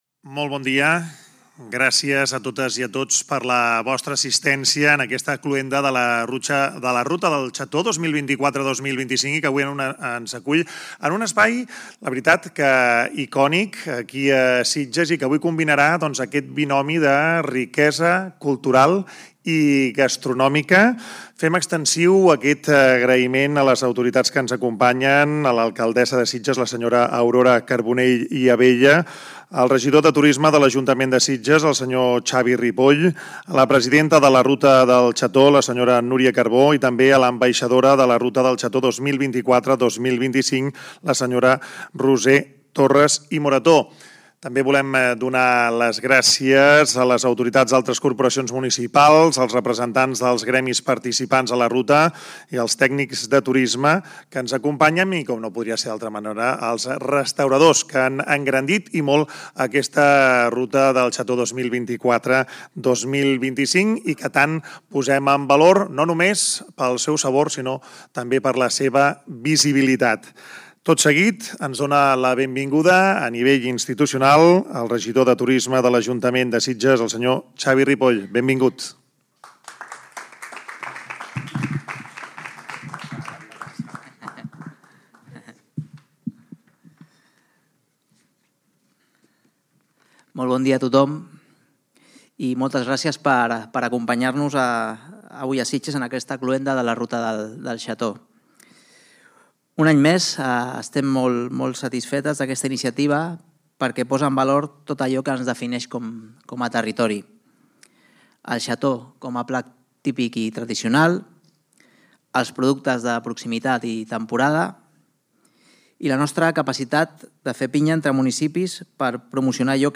La Ruta del Xató 2024-25 s’ha tancat a l’Escorxador davant de representants polítics, membres dels diferents gremis d’hostaleria del territori i tècnics de turisme dels municipis participants. Es posa punt i final a una temporada que ha mirat de consolidar el xató com un plat referent al calendari gastronòmic català i més enguany coincidint amb que Catalunya es regió mundial de la gastronomia. Ha obert l’acte de cloenda el regidor de turisme, Xavi Ripoll i l’ha clos l’alcaldessa Aurora Carbonell.